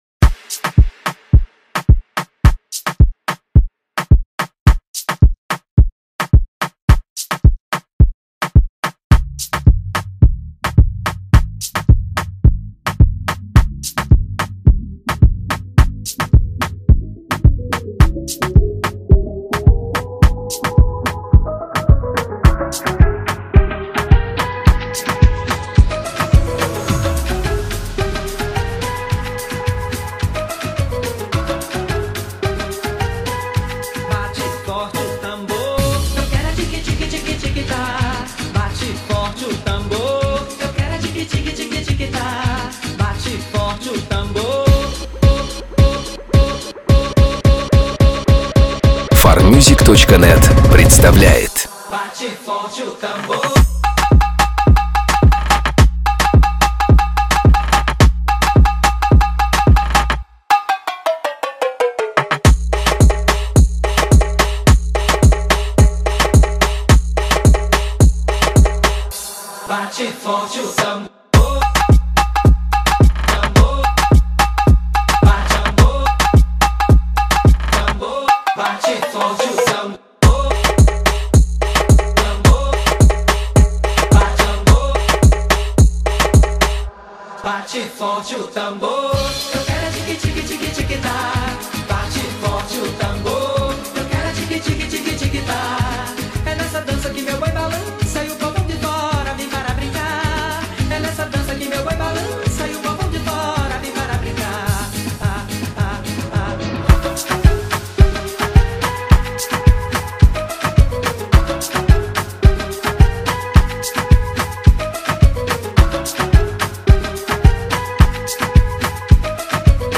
Клубные песни